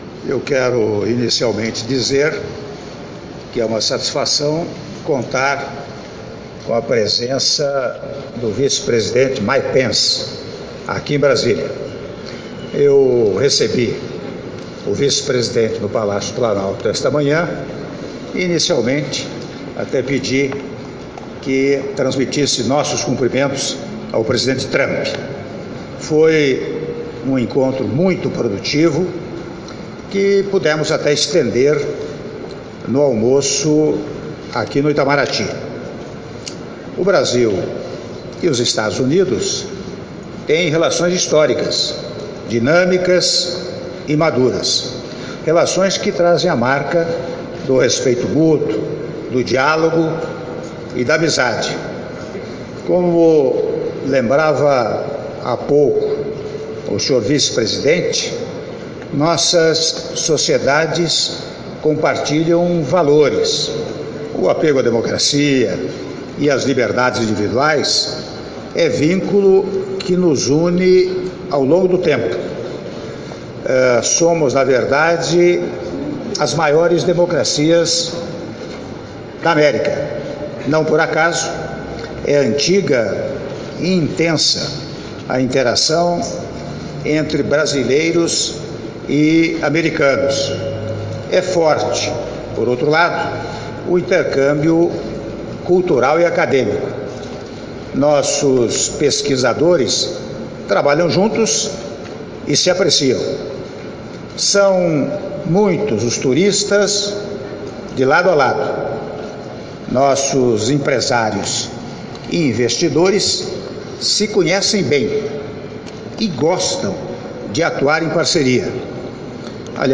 Áudio da Declaração à imprensa do presidente da República, Michel Temer, após almoço com o vice-presidente dos Estados Unidos, Michael Pence - (08min35s)